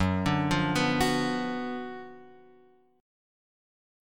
F#M7sus4#5 chord {2 5 3 4 x 2} chord